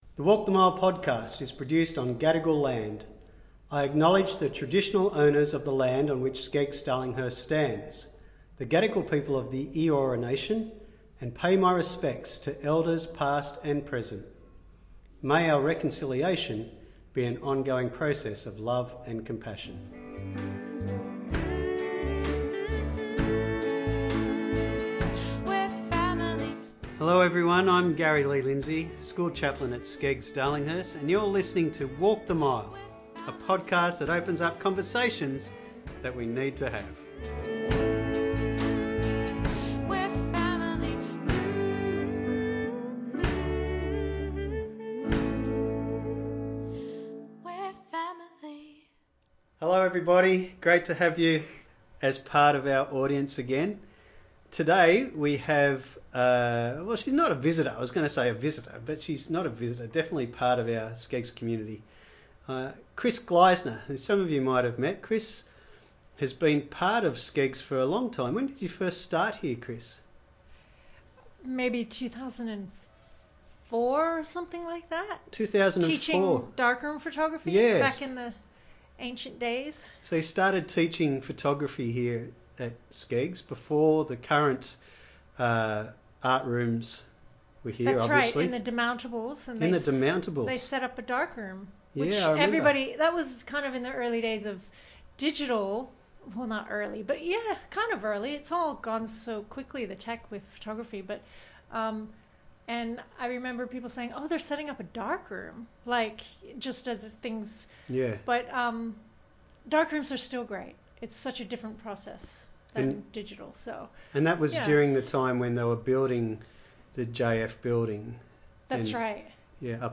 It was recorded last term, before the lockdown.